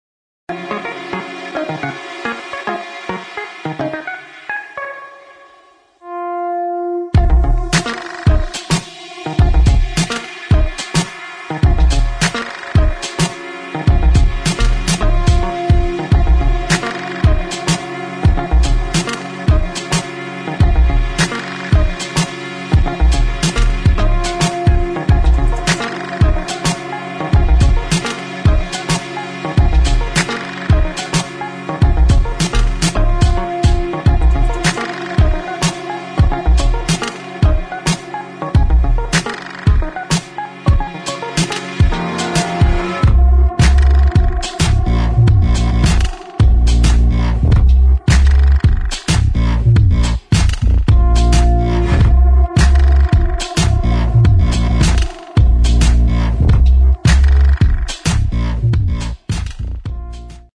[ DOWNTEMPO | ELECTRONIC ]